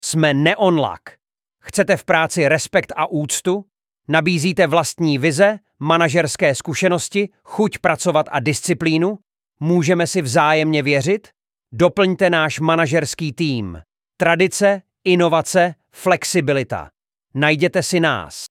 Práce v oddělení managementu (audiospot)